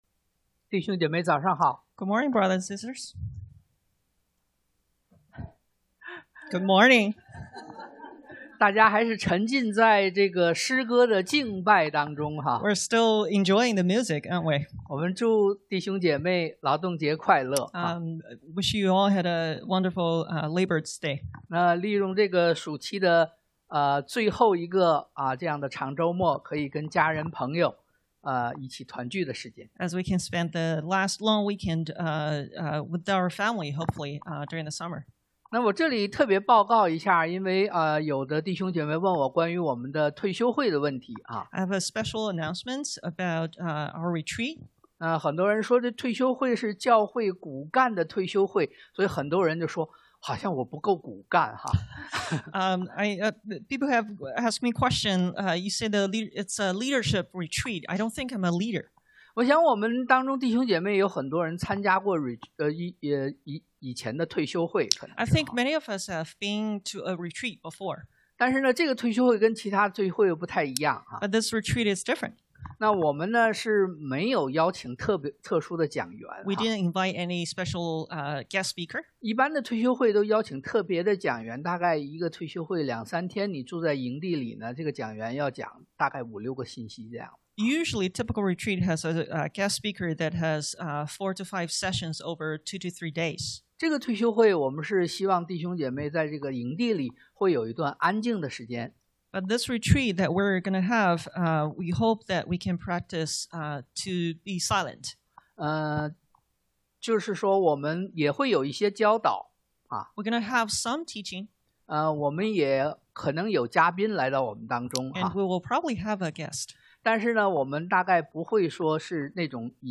但 Dan 4:19-37 Service Type: Sunday AM 1.